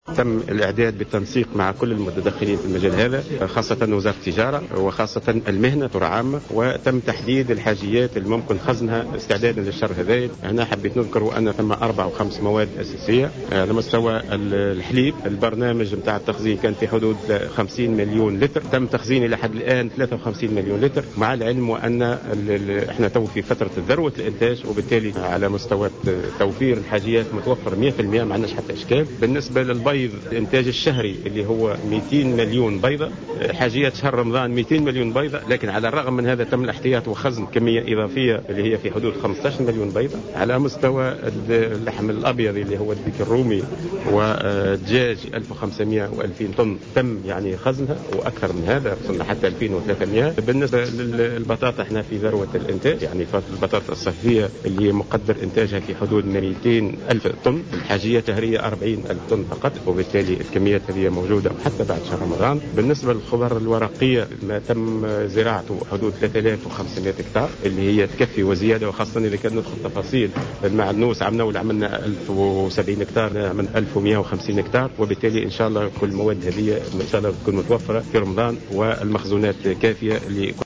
أعلن وزير الفلاحة والموارد المائية والصيد البحري سعد الصديق خلال ندوة صحفية عقدها اليوم في مقر الوزارة حول الاستعدادت لشهر رمضان المقبل أنه تم بالتنسيق مع وزارة التجارة تحديد الحاجيات الممكن خزنها خاصة المواد الأساسية من بينها تخزين 53 مليون لتر من الحليب.